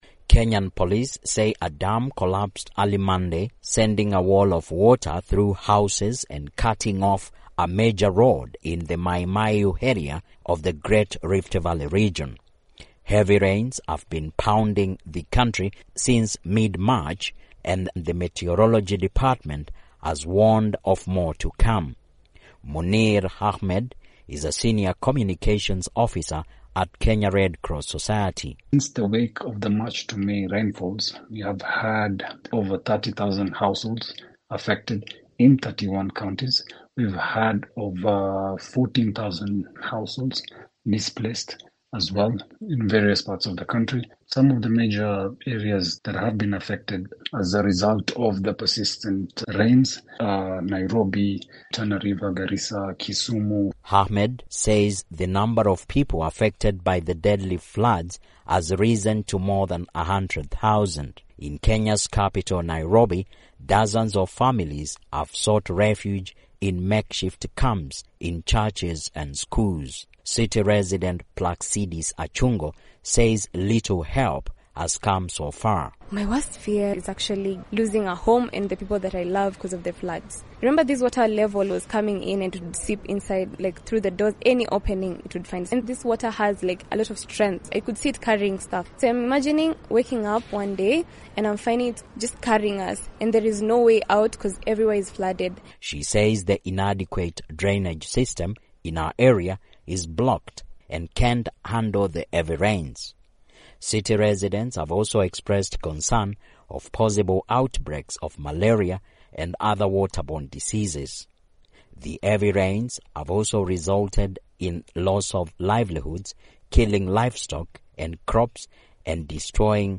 reports from Nairobi